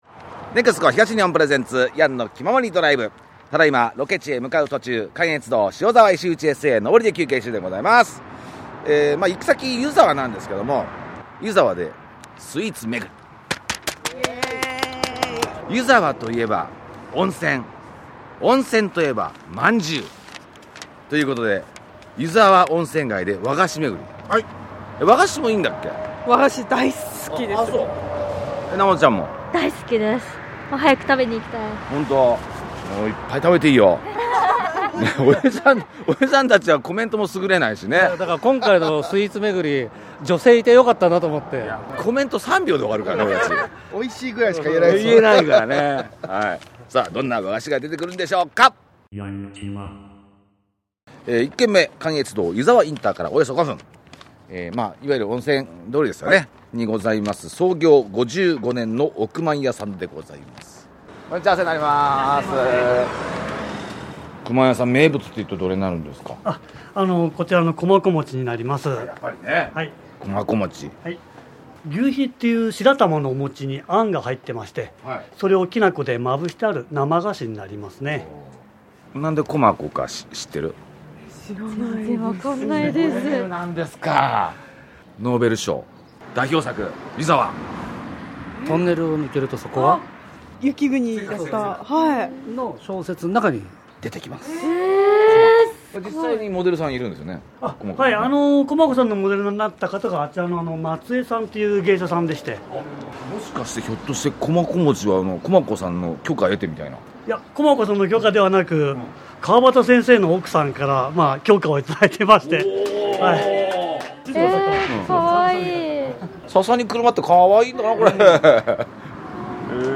1軒目！関越道・湯沢ICから約５分、温泉街通りの「億萬屋」さん。